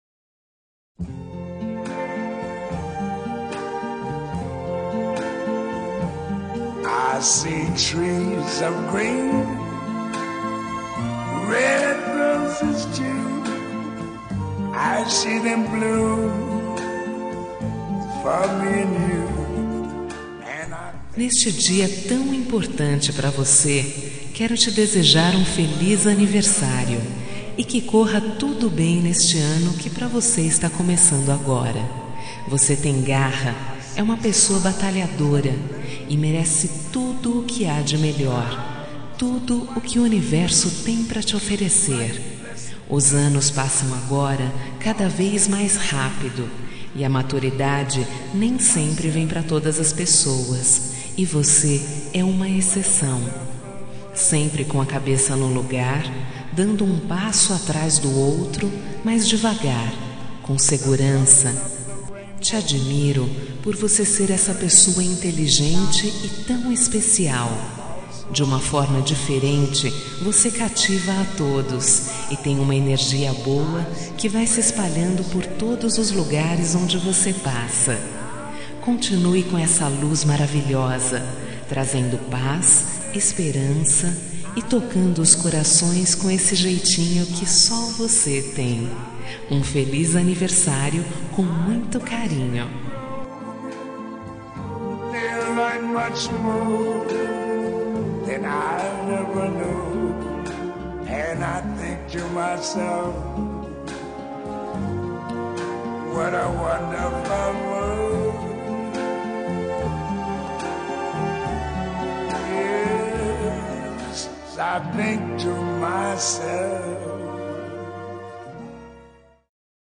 Aniversário de Pessoa Especial – Voz Feminina – Cód: 1888